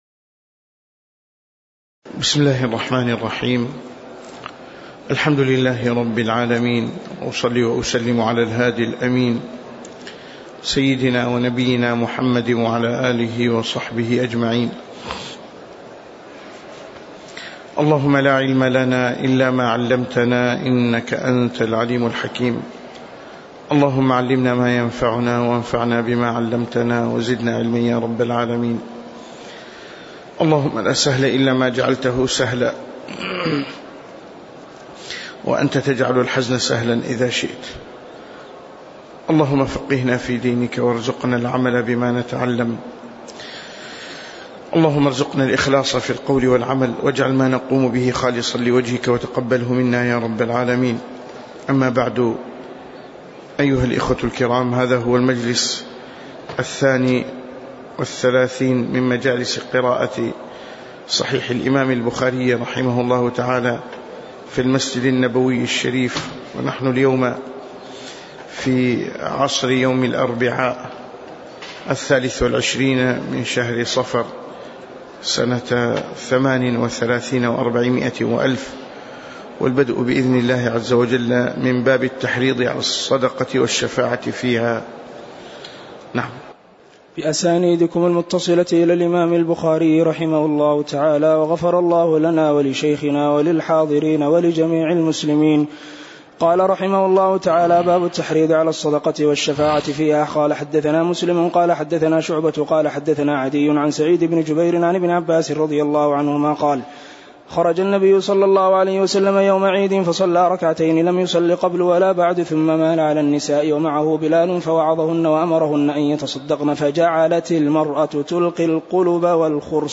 تاريخ النشر ٢٣ صفر ١٤٣٨ هـ المكان: المسجد النبوي الشيخ